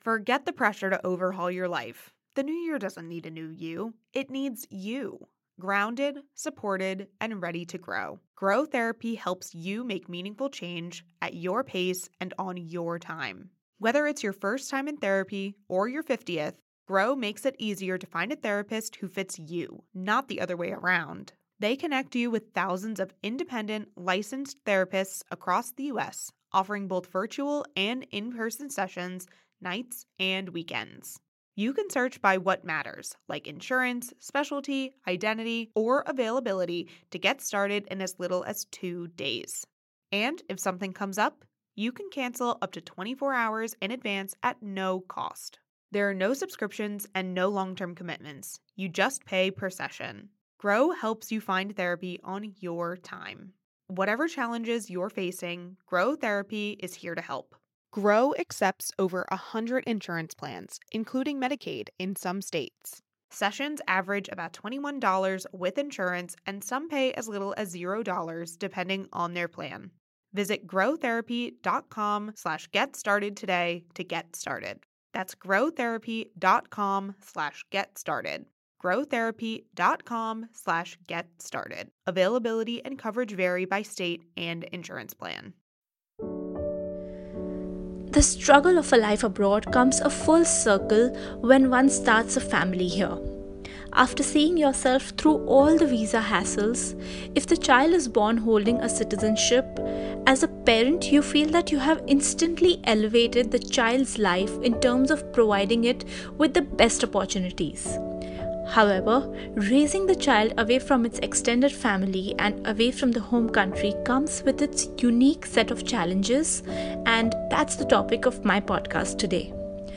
joins Culture Chaos in a phone interview and shares memories and stories from her childhood.